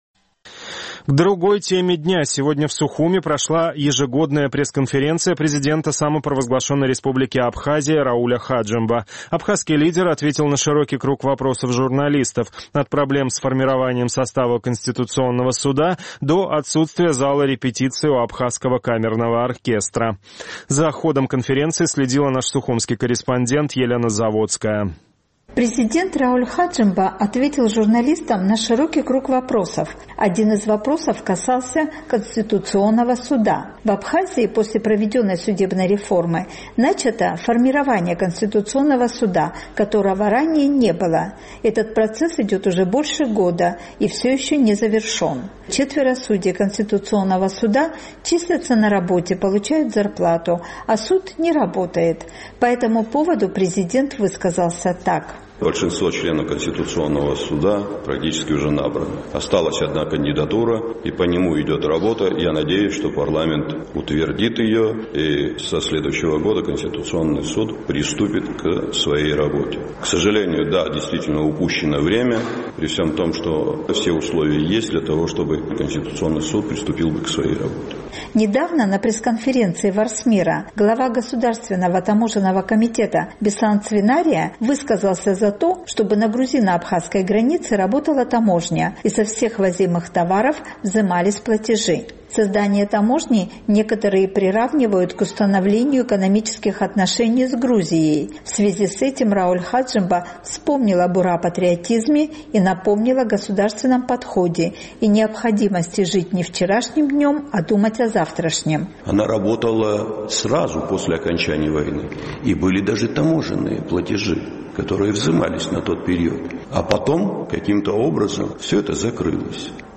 Сегодня в Сухуме прошла ежегодная пресс-конференция президента Абхазии Рауля Хаджимба. Глава государства ответил на широкий круг вопросов журналистов – от проблем с формированием состава Конституционного суда до отсутствия зала репетиций у Абхазского камерного оркестра.